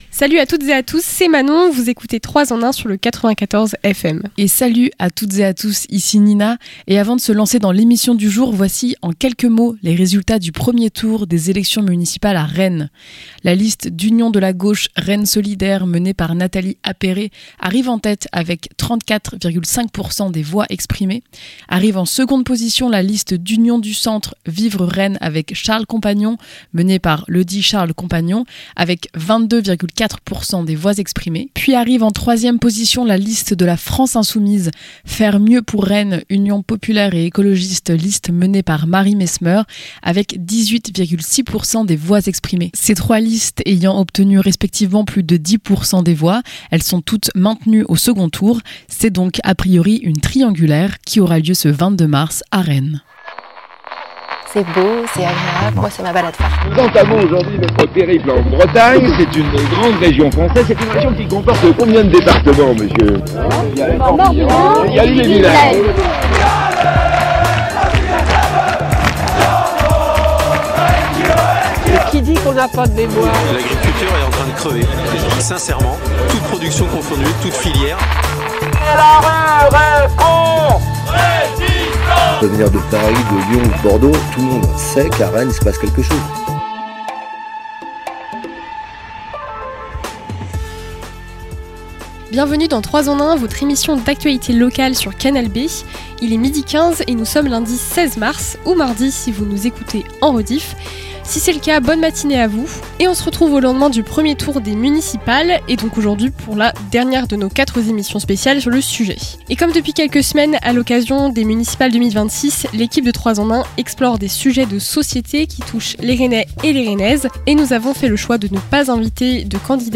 À travers des micro-trottoirs et des interventions de spécialistes, l'objectif est de comprendre les enjeux de ces problématiques et du rôle de la mairie dans leurs potentielles évolutions.